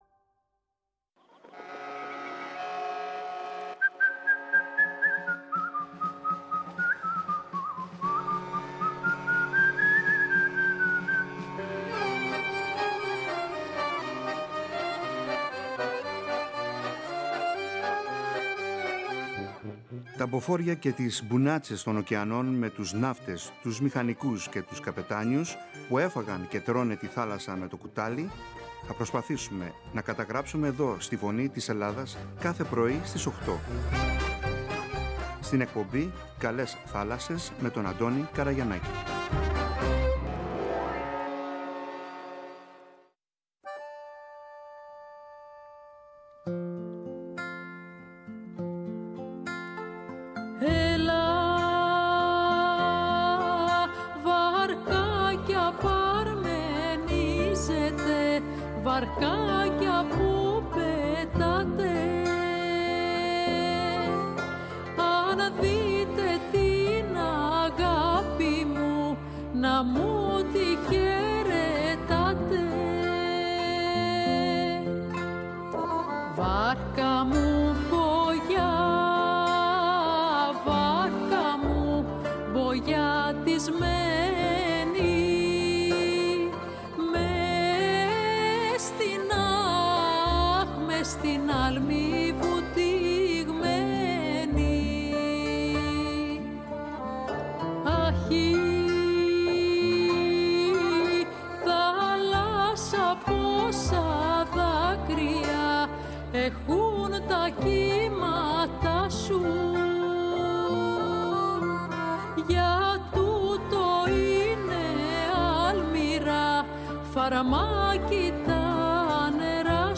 Είναι πιο πολύ ανθρωποκεντρική εκπομπή για τον ναυτικό, με τα θέματα του, τη ναυτιλία, τραγούδια, ιστορίες, συναισθήματα, σκέψεις, και άλλα πολλά όπως π.χ η γυναίκα εργαζόμενη στη ναυτιλία, η γυναίκα ναυτικού, είδη καραβιών, ιστορίες ναυτικών οικογενειών, ιστορίες ναυτικών, επικοινωνία μέσω του ραδιοφώνου, ναυτικές ορολογίες, τραγούδια, ποίηση, πεζογραφία για τη θάλασσα, εξαρτήματα του πλοίου, ήδη καραβιών ναυτικά επαγγέλματα κλπ κλπ Ήδη έχουν ανταποκριθεί αρκετοί, παλιοί και εν ενεργεία καπετάνιοι και μίλησαν με μεγάλη χαρά και συγκίνηση για την ζωή – καριέρα τους στην θάλασσα και τι σημαίνει για αυτούς.